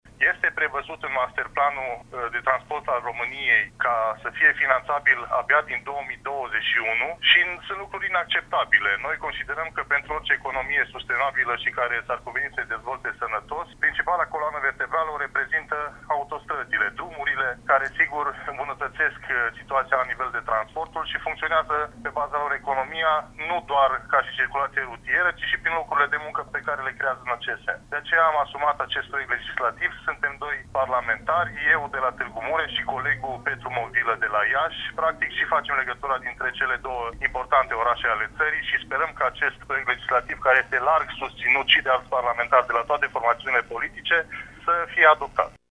Deputatul PMP Mureș, Marius Pașcan: